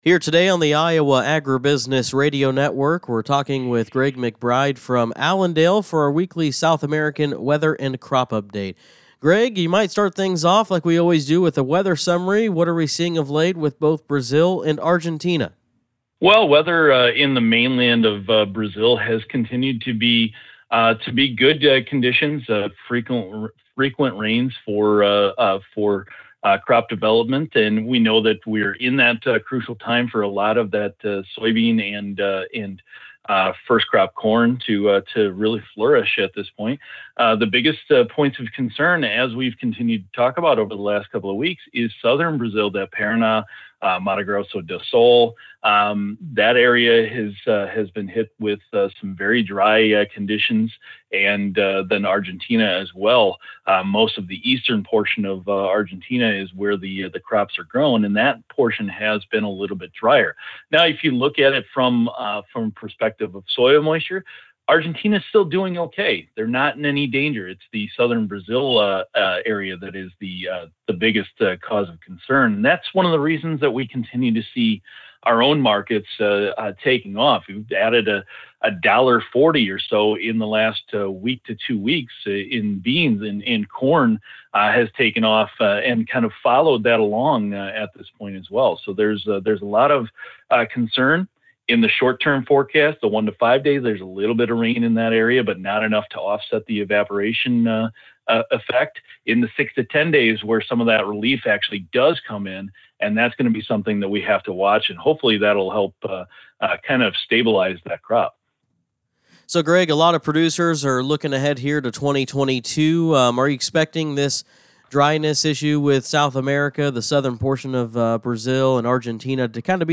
AUDIO REPORT: Weekly South American Weather & Crop Update